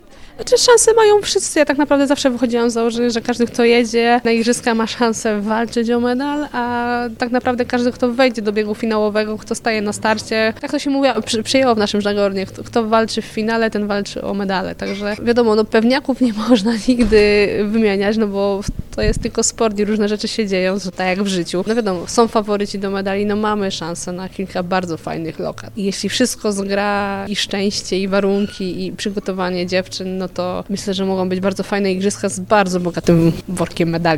Podczas igrzysk w Tokio cieszyliśmy się z brązowego medalu czwórki kajakowej w konkurencji na 500 m. Kto ma szansę na zwycięstwo w Paryżu? Ocenia Marta Walczykiewicz.
Na całą rozmowę z Martą Walczykiewicz o kajakarstwie, olimpiadach i powodach, dla których warto odwiedzić Kalisz, zapraszamy już dziś po 16:00!